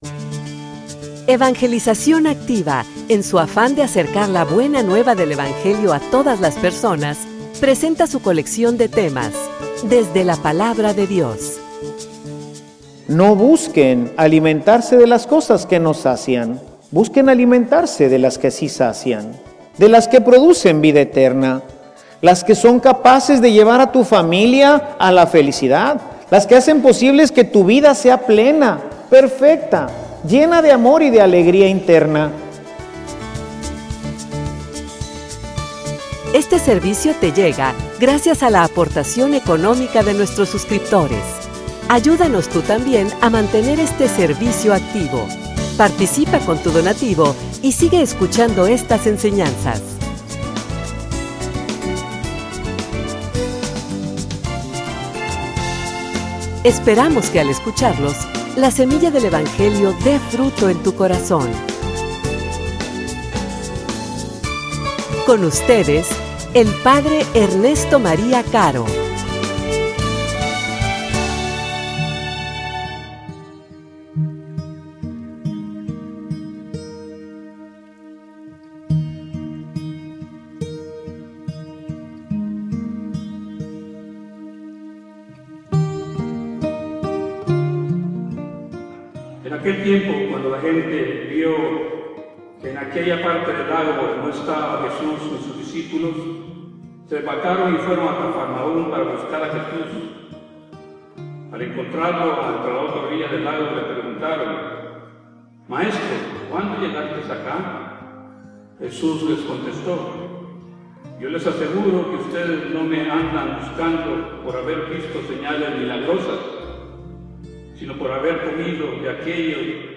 homilia_De_que_te_alimentas.mp3